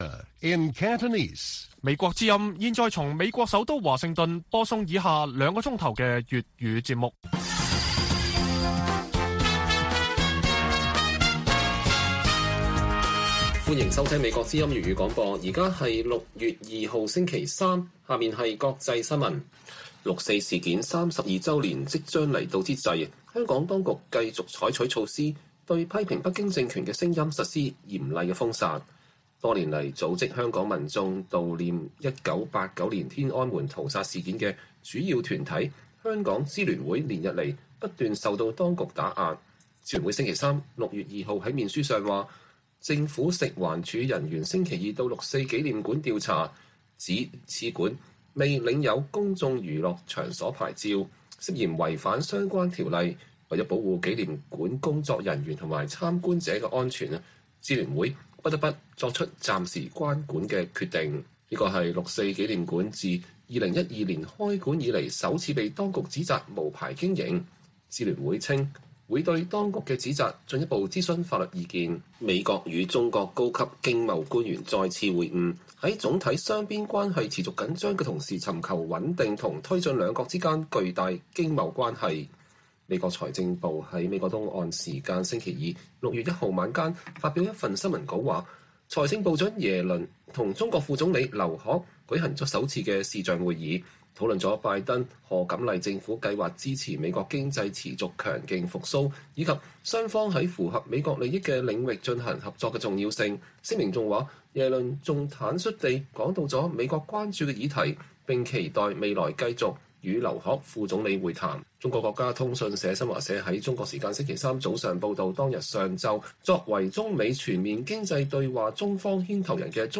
粵語新聞 晚上9-10點: 香港當局以無牌經營迫使六四紀念館關閉